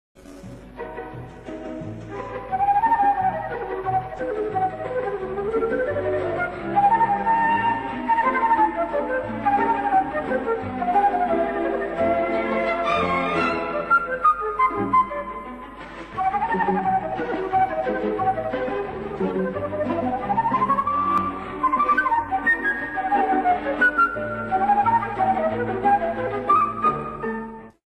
für Solo-Flöte und kleines Orchester bzw. Klavier